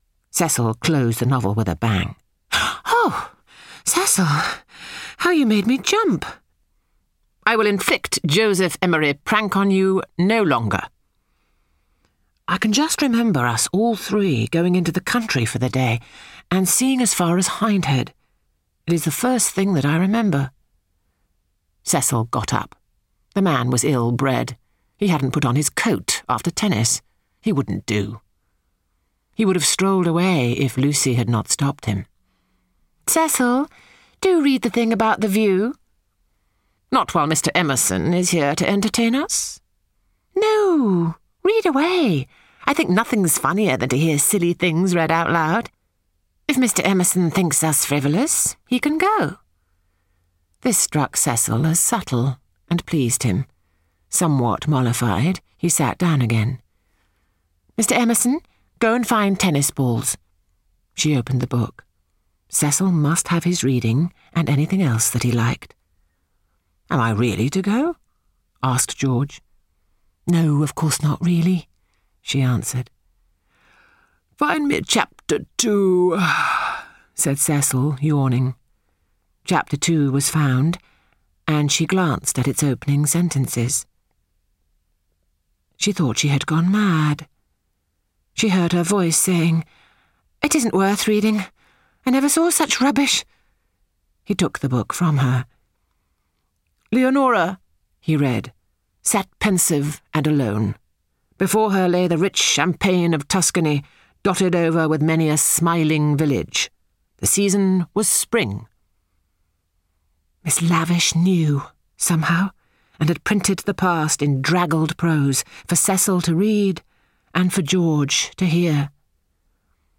英文广播剧在线听 A Room With A View 47 听力文件下载—在线英语听力室